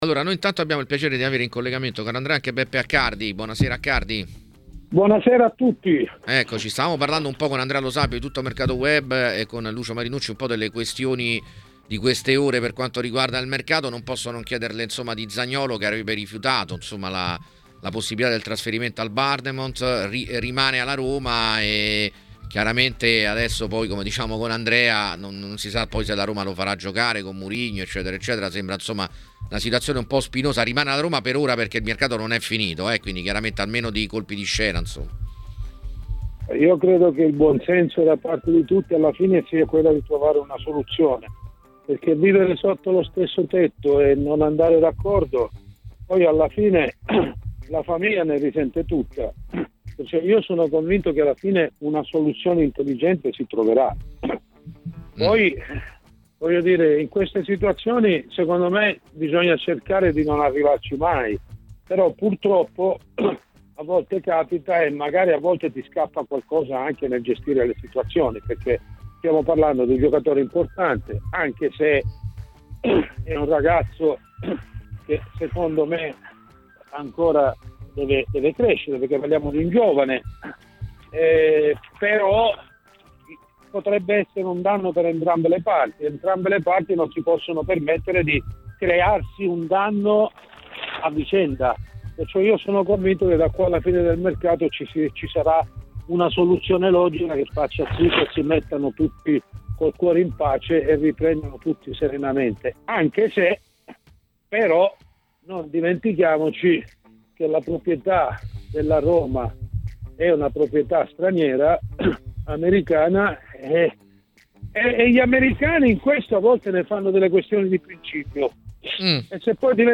è intervenuto ai microfoni di TMW Radio, durante la trasmissione Piazza Affari, per commentare alcuni temi di calciomercato.